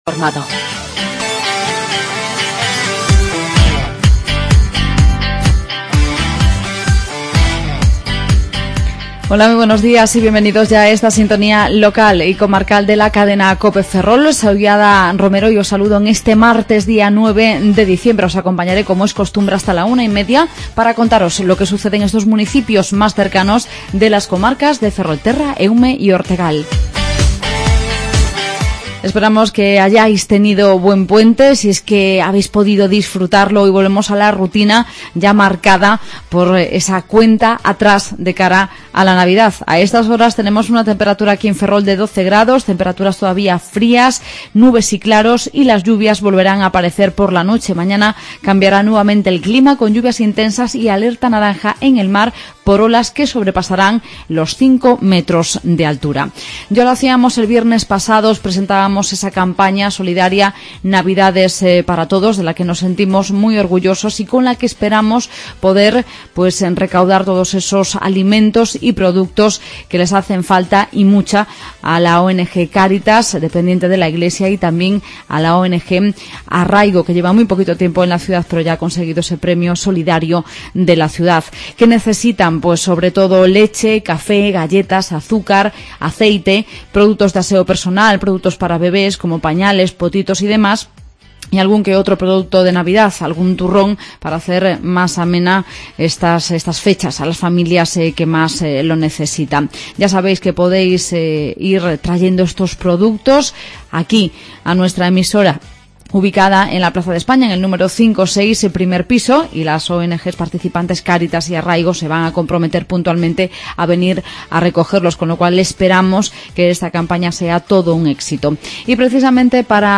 Redacción digital Madrid - Publicado el 09 dic 2014, 13:57 - Actualizado 14 mar 2023, 00:46 1 min lectura Descargar Facebook Twitter Whatsapp Telegram Enviar por email Copiar enlace Magazine local y comarcal. Hoy hablamos de la campaña "Navidades para todos" y del proyecto de huertos urbanos en As Pontes, entre otros contenidos.